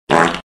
Fart Meme Sound Toot Sound Button - Free Download & Play
Fart Soundboard9,309 views